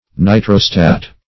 Nitrostat - definition of Nitrostat - synonyms, pronunciation, spelling from Free Dictionary